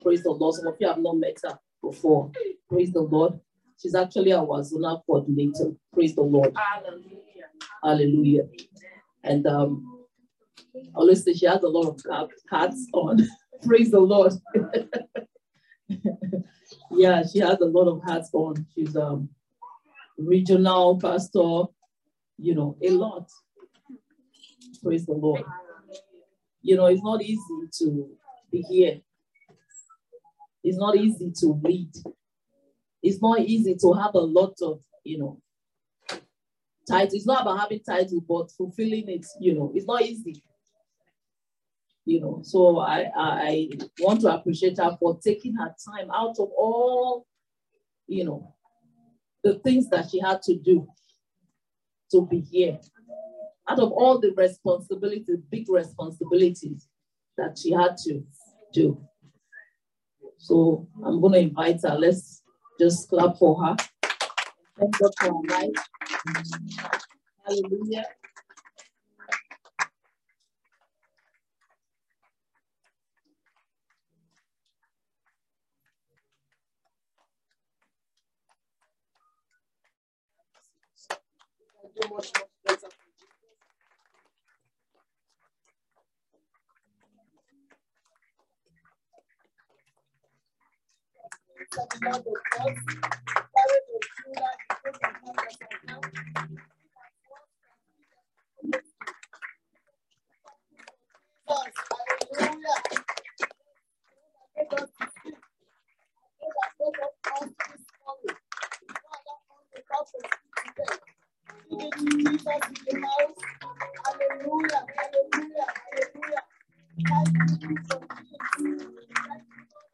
Esther 4:1-17 Service Type: Sunday Service Today is the last day of Women’s Week.